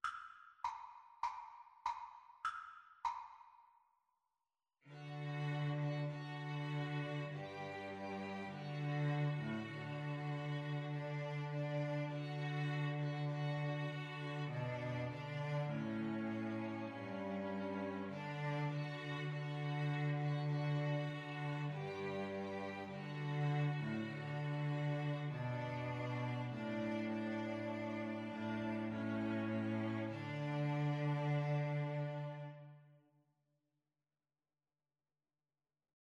Free Sheet music for String trio
D major (Sounding Pitch) (View more D major Music for String trio )
Cantabile =c.100
4/4 (View more 4/4 Music)